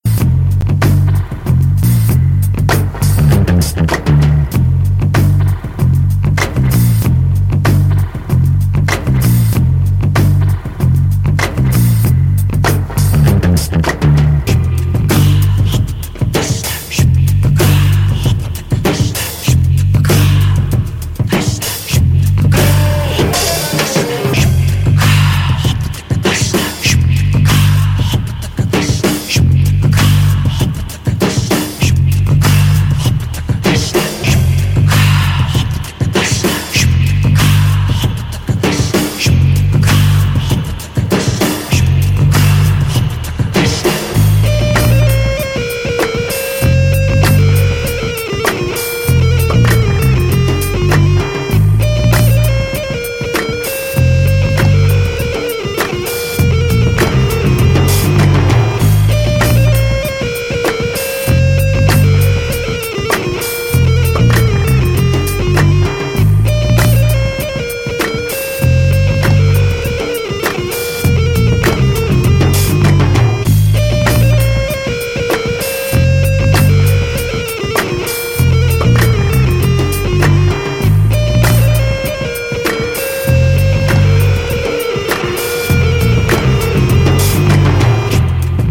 Disco Funk Outernational